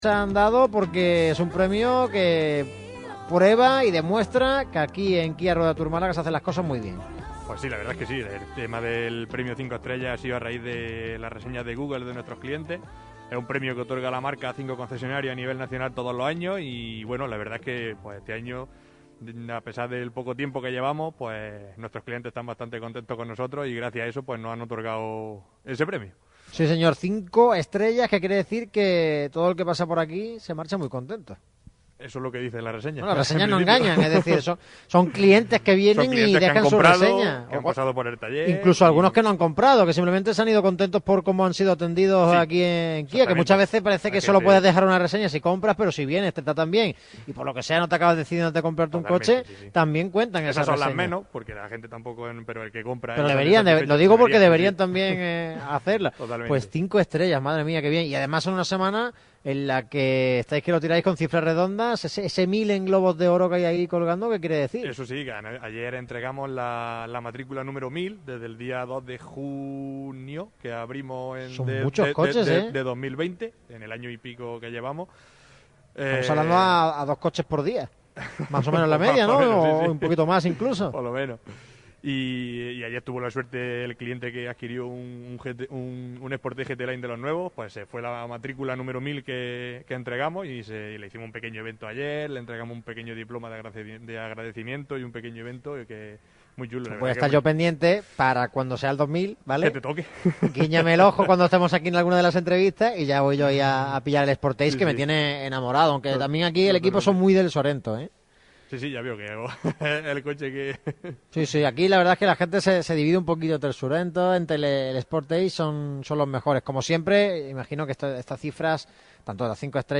Radio Marca Málaga se desplaza este miércoles hasta Ruedatur KIA, concesionario oficial de la marca surcoreana en la Costa del Sol. Un programa cargado de debate, aún con las secuelas de ese último día del mercado de fichajes, y mucha información de los principales equipos de la provincia.
Unas gran tertulia de radio, centrada en la complicada situación que se vive en Martiricos, con varios puntos encima de la mesa.